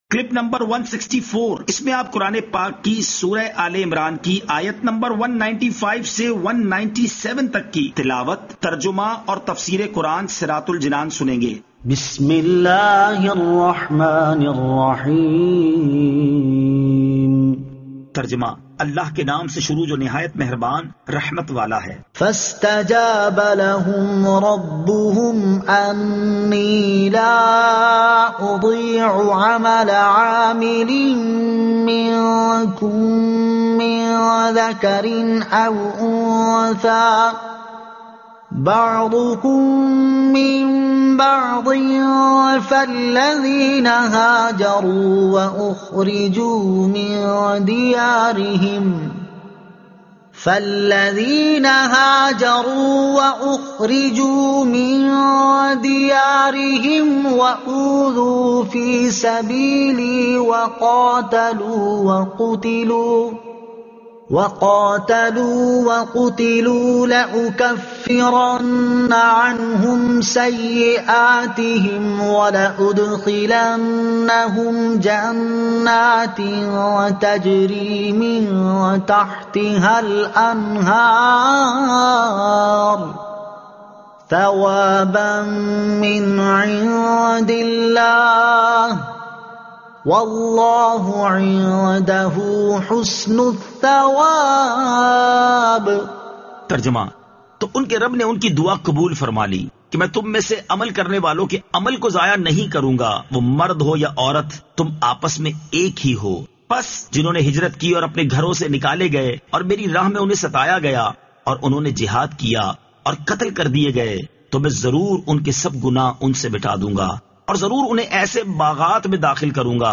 Surah Aal-e-Imran Ayat 195 To 197 Tilawat , Tarjuma , Tafseer